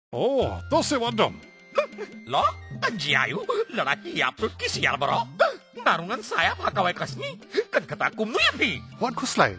Radio PSA